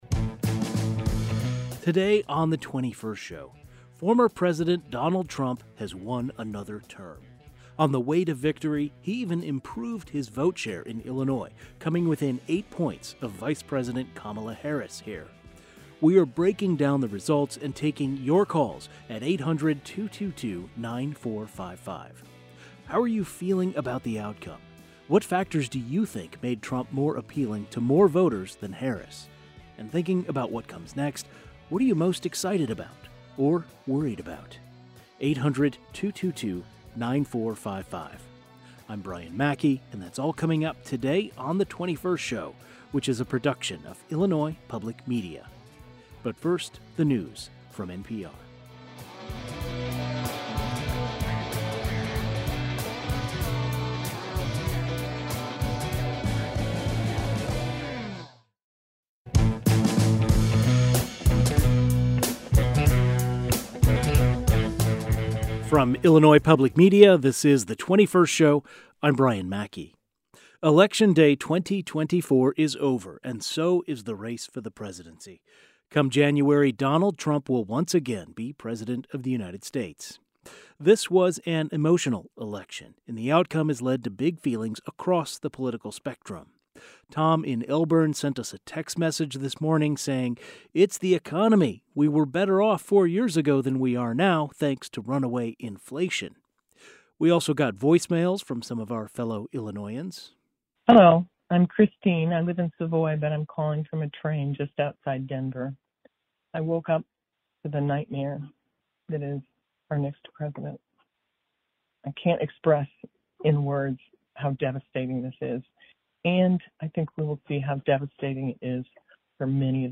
A panel of political scientists and journalists help us make sense of last night's election results.